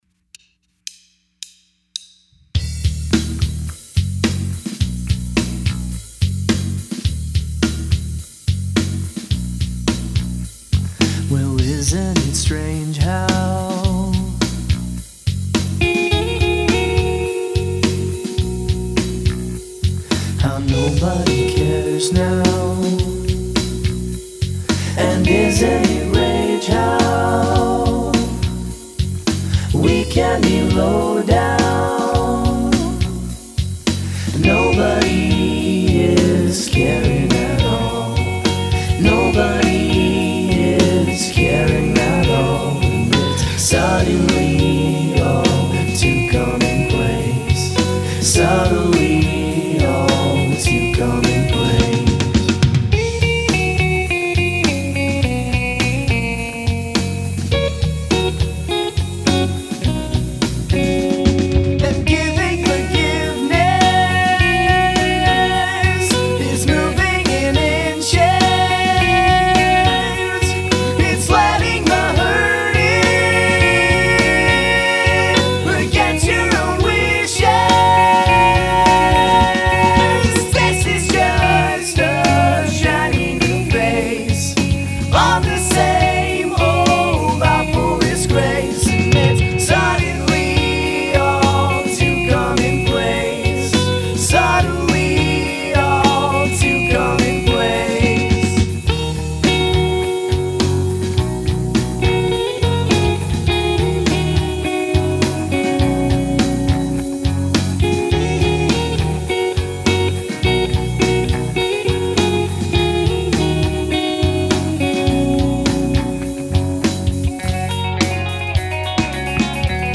Fun little rock song (with a singer that didn't need Melodyne!)
I recorded this track at my college a couple years ago, and I finally decided to take another crack at it now that I've gotten better at mixing.